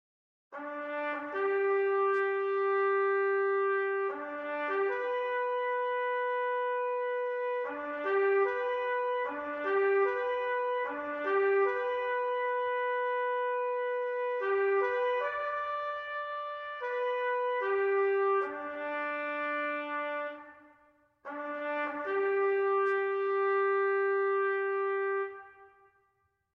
Traditional Bugle Call : Taps